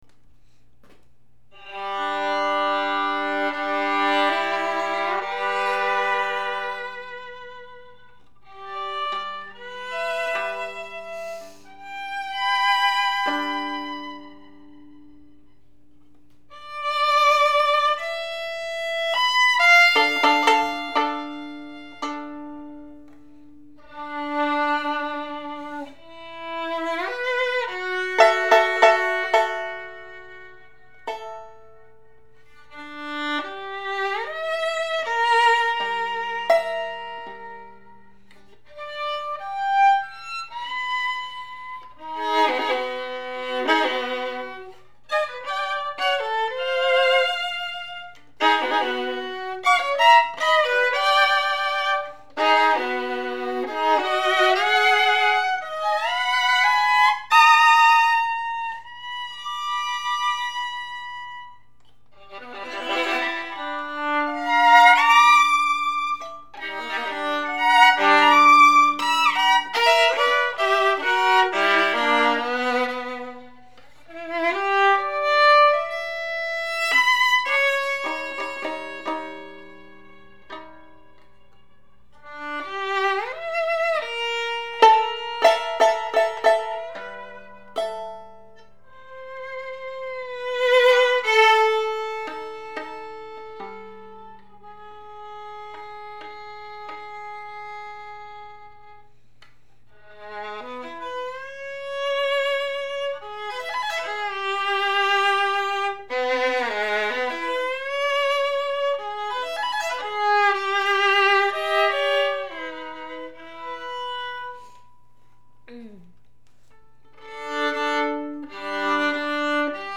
その結果、音量のある深みのある音質です。